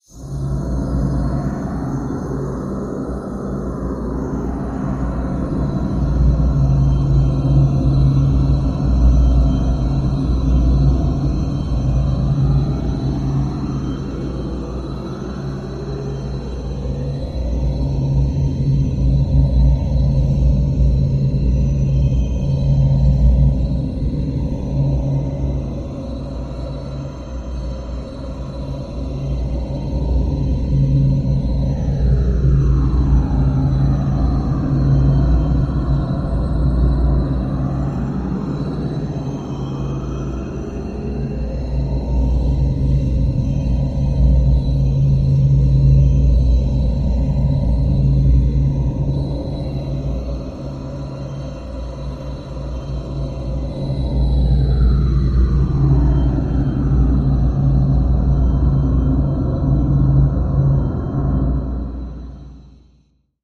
Morph Shimmer wind with heavy flanging and reverberate chimes